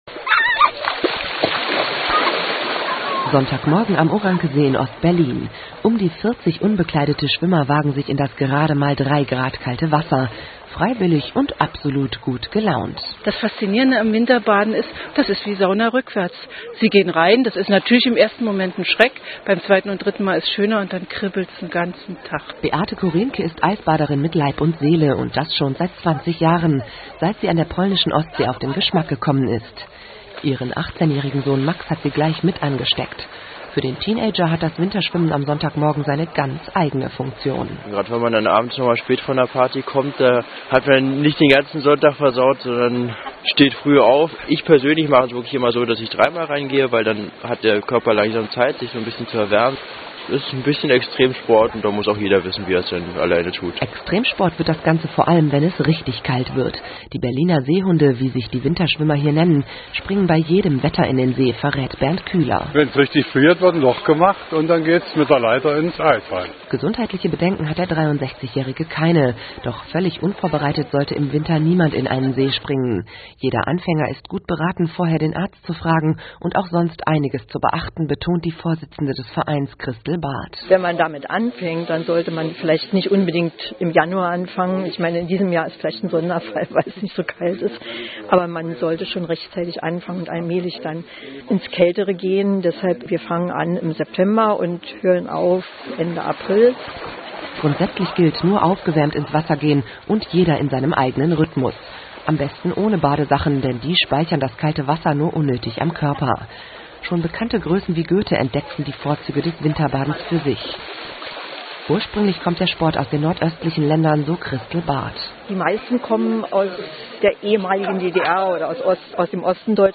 mp3, 670 kB, 2min45sec Rundfunkbeitrag mit mehreren Berliner Seehunden,